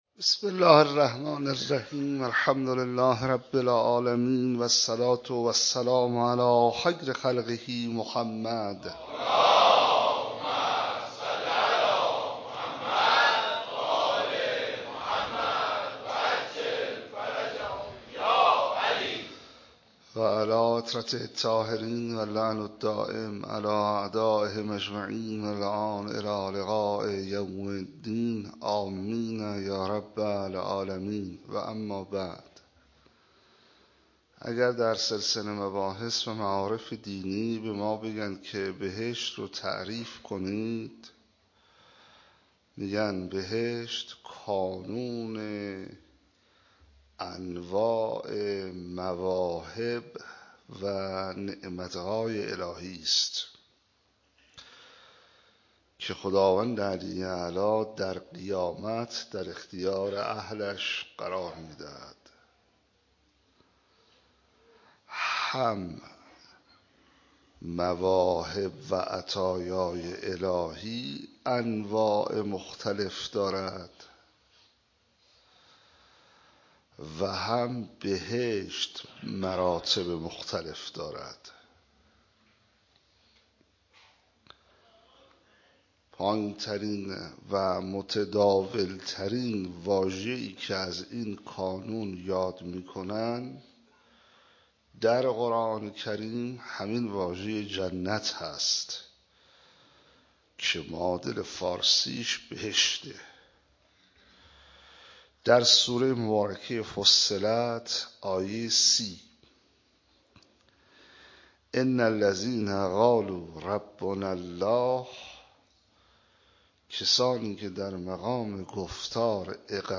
13 فروردین 97 - حسینیه حق شناس - سخنرانی